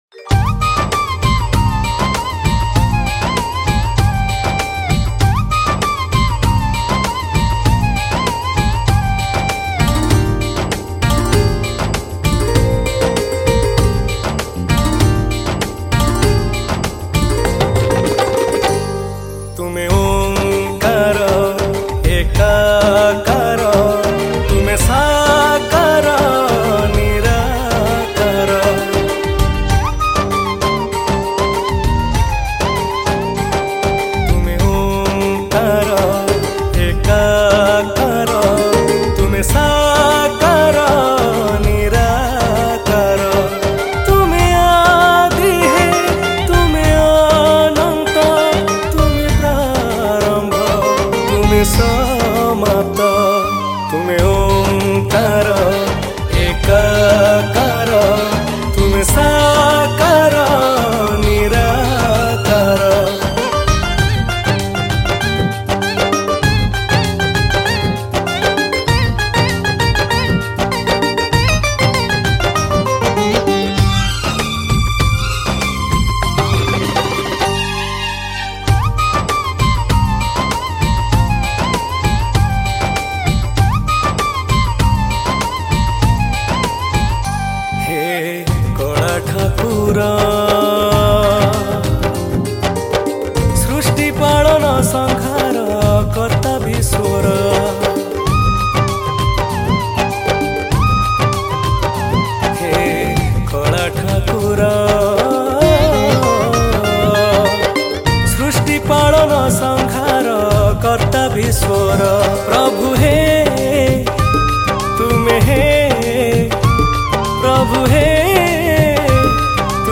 Odia Bhajan Song 2025 Songs Download